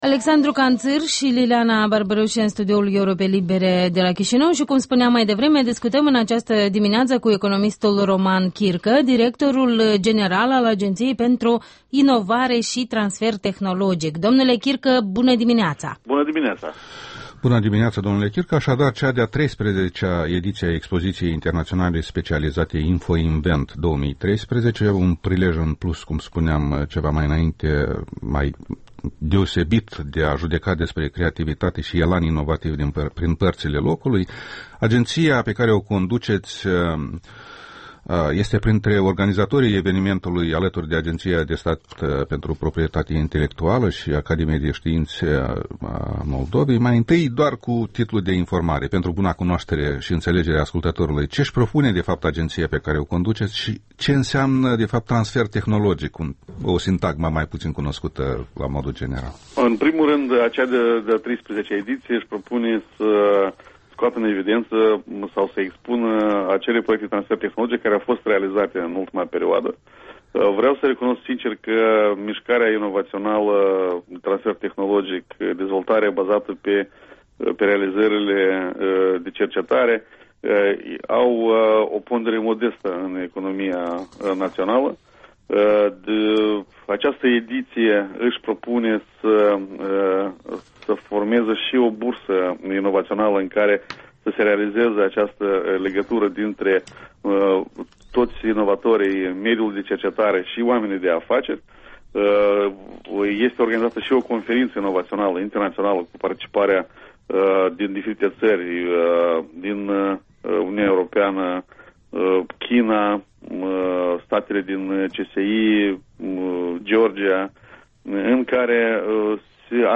Interviul dimineții: cu Roman Chircă, directorul general al Agenției pentru Inovare